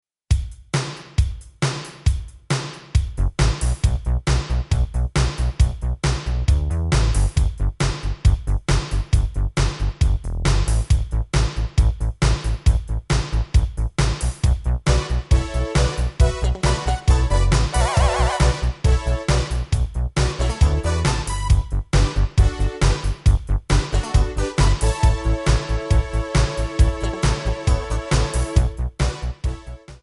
Backing track files: Disco (180)